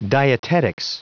Prononciation du mot : dietetics